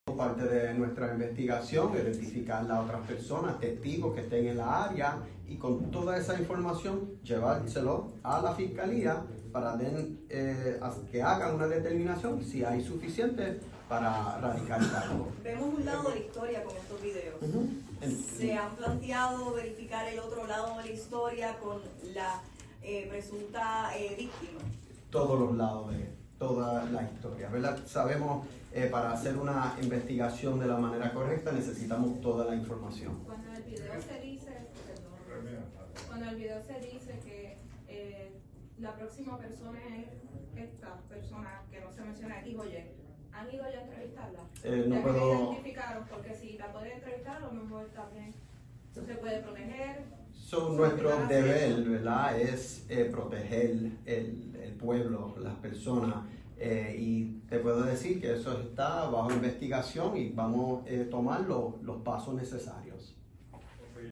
Parte de nuestra investigación es identificar las otras personas testigos que estén en la área y con toda esa información llevárselo a la fiscalía para que hagan una determinación si hay suficiente radicar cargos. […] (Se han planteado) todos los lados de toda la historia. Sabemos (que) para hacer una investigación de la manera correcta necesitamos toda la información“, indicó González en conferencia de prensa.